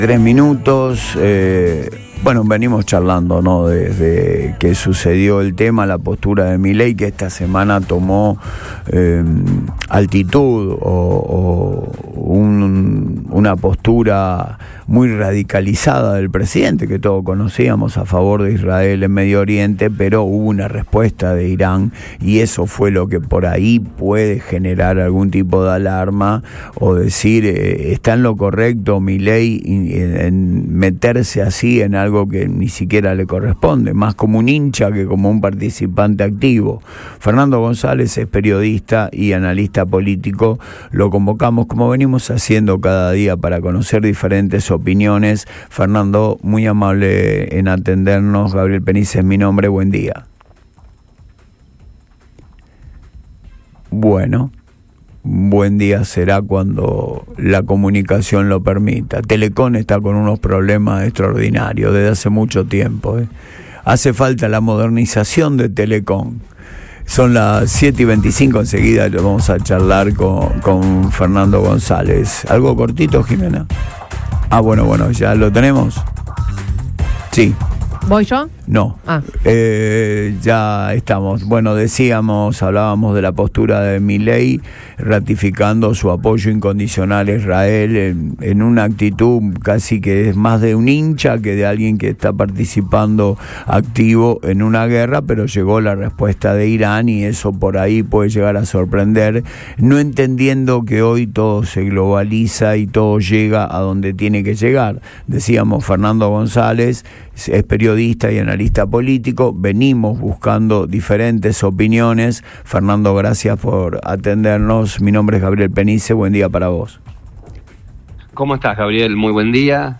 La entrevista comenzó abordando el reciente editorial del Tehran Times, donde el régimen iraní acusó a Milei de cruzar una “línea roja”.